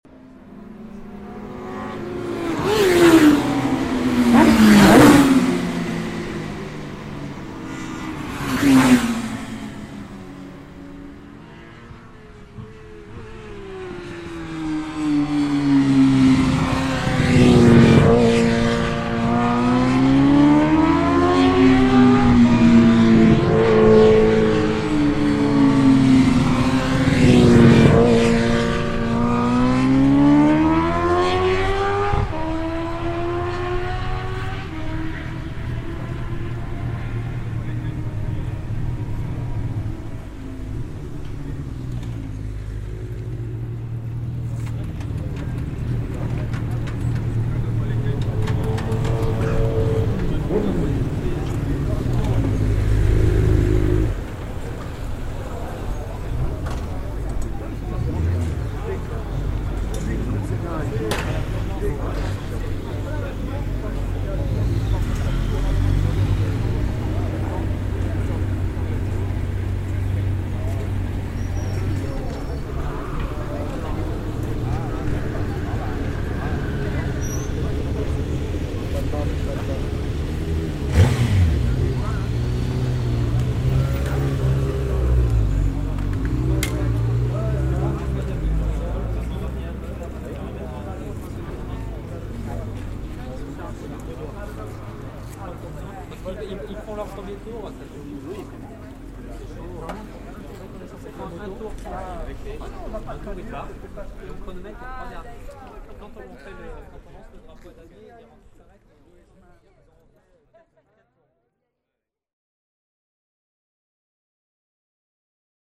oreilles avec de délicieux chants de moteurs et d'échappements qui nous font si souvent
poignet dans le coin.mp3